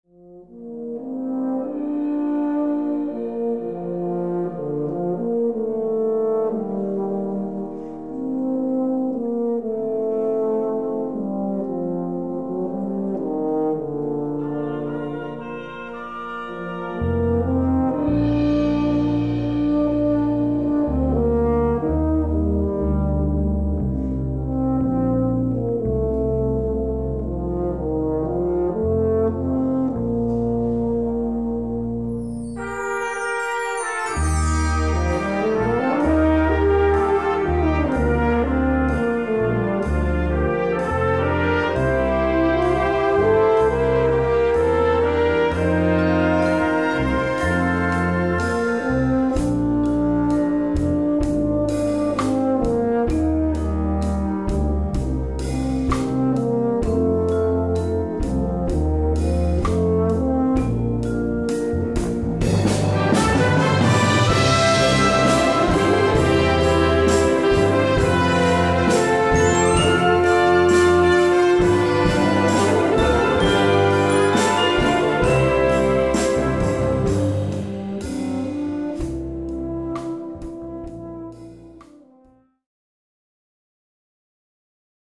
Gattung: Solo für Euphonium und Blasorchester
Besetzung: Blasorchester